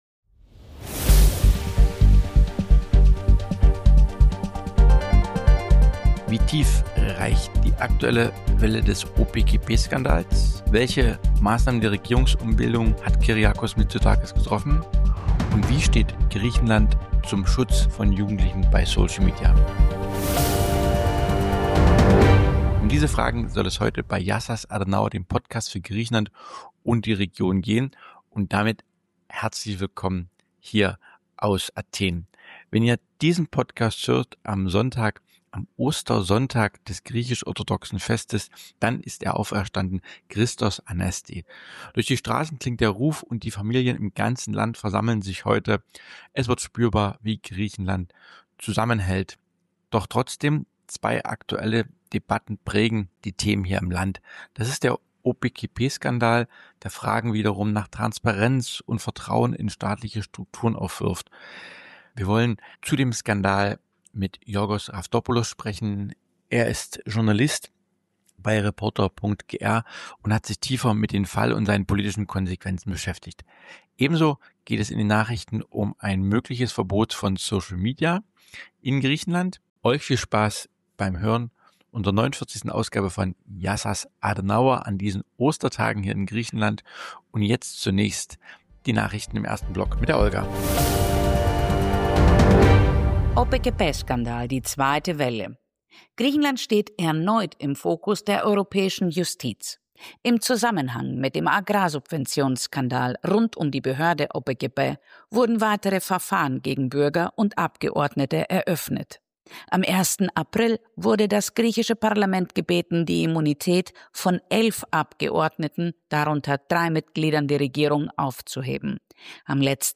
Es geht um den Verdacht auf missbräuchlich verwendete EU-Agrarsubventionen, mögliche politische Verantwortung und die Frage, wie tief die Affäre in staatliche Strukturen hineinreicht. Host Marian Wendt spricht mit dem Journalisten